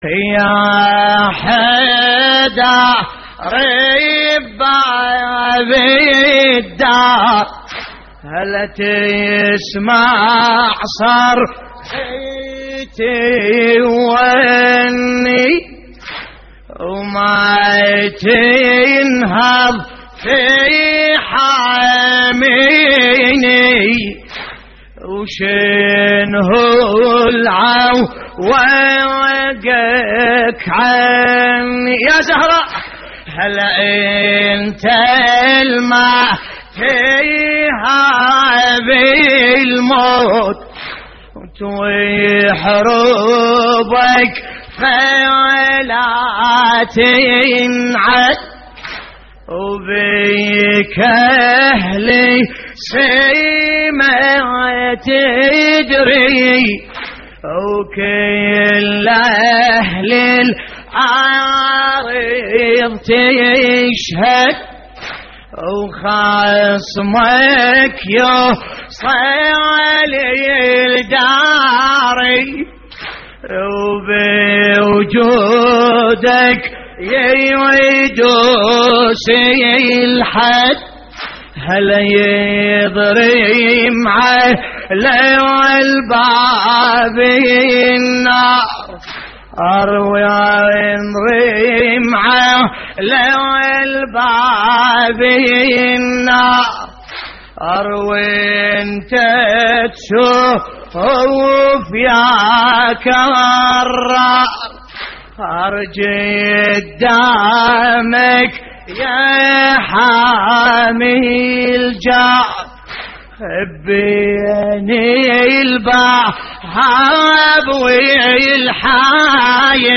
تحميل : يا حيدر بباب الدار تسمع صرختي ووني / الرادود باسم الكربلائي / اللطميات الحسينية / موقع يا حسين